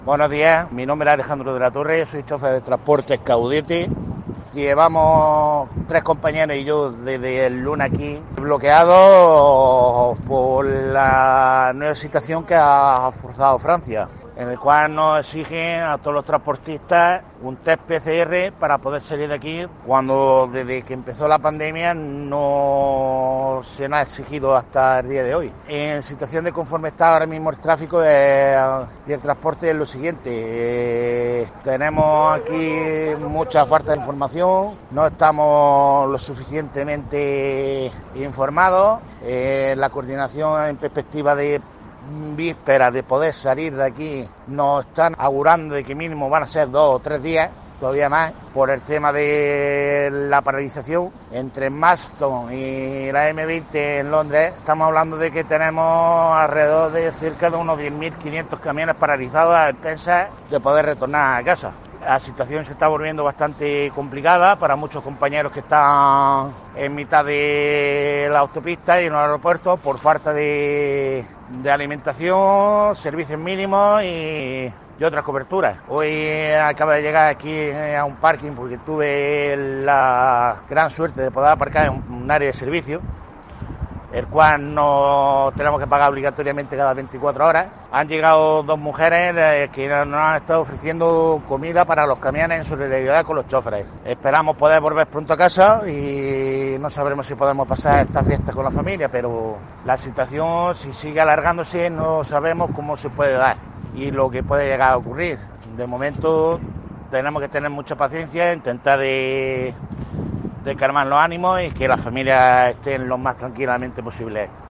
DESDE REINO UNIDO